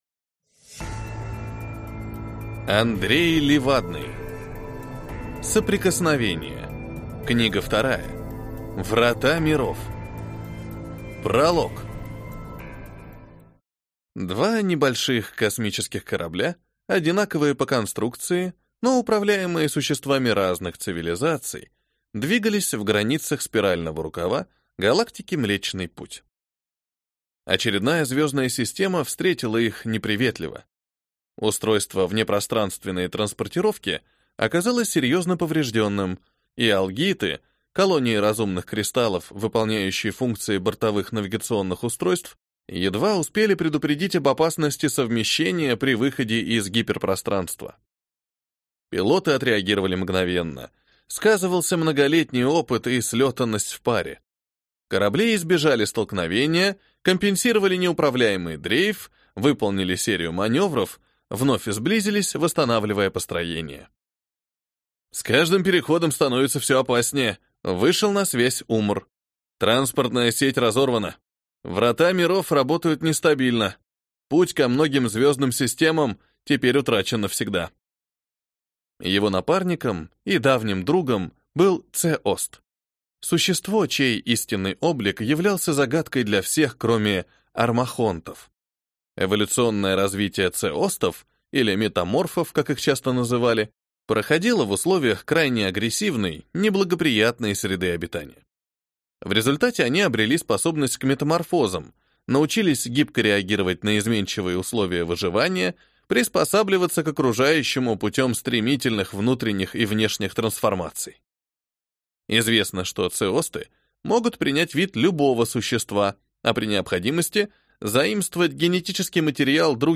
Аудиокнига Врата Миров | Библиотека аудиокниг